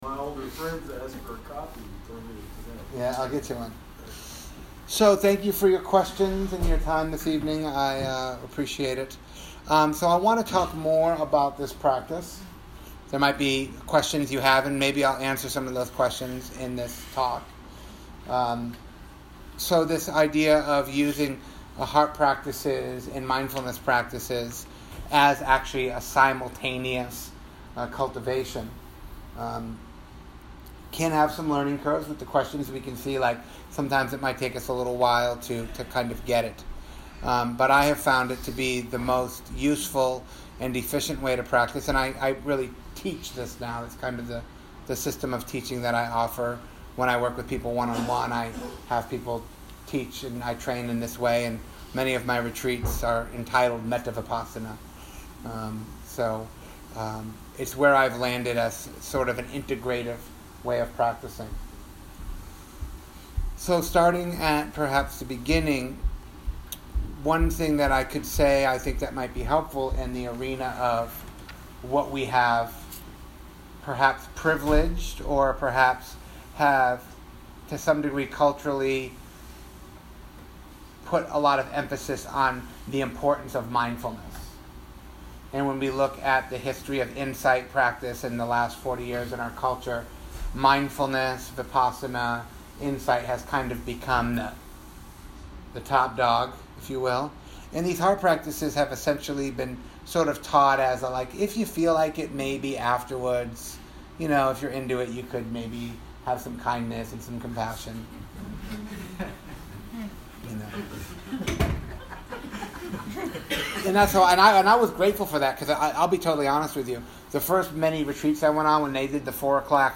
This talk was given at the Urban Dharma Center in Asheville NC. Vipassana meditation unites the stillness of concentration with the immediacy of moment to moment experience.